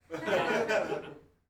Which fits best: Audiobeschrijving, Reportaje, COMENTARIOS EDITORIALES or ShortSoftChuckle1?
ShortSoftChuckle1